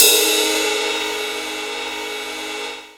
43_06_ride.wav